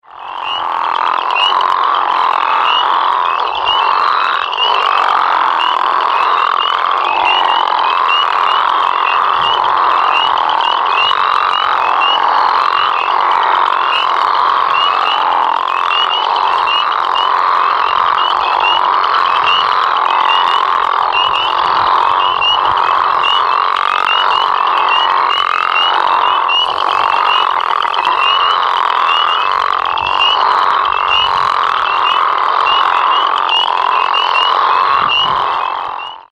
Crawfish Frog (Lithobates areolatus ssp.)